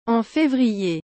en févrierアォン フェヴリエ